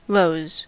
Ääntäminen
Ääntäminen US Tuntematon aksentti: IPA : /ləʊz/ Haettu sana löytyi näillä lähdekielillä: englanti Käännöksiä ei löytynyt valitulle kohdekielelle. Lows on sanan low monikko.